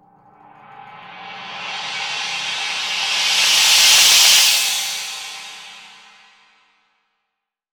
Index of /90_sSampleCDs/AKAI S6000 CD-ROM - Volume 3/Crash_Cymbal2/MALLET_CYMBAL